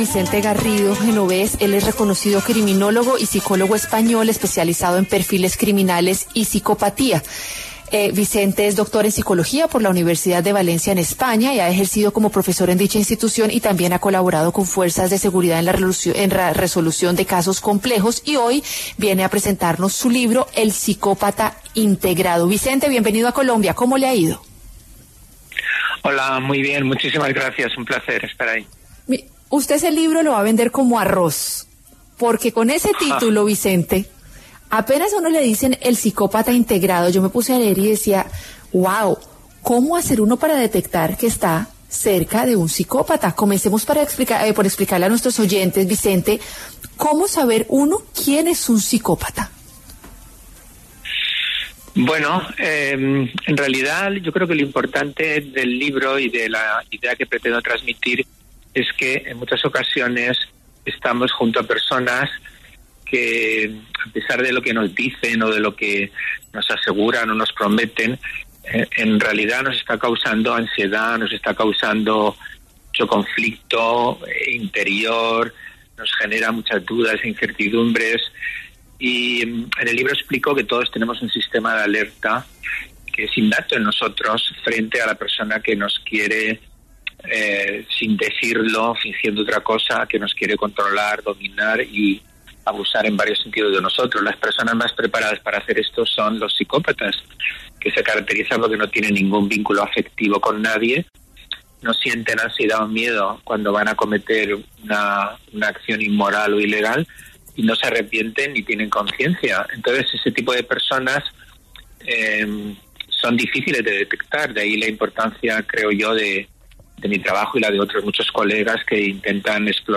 Vicente Garrido, reconocido criminólogo español, habló en Salud y Algo Más sobre los indicios para identificar un psicópata.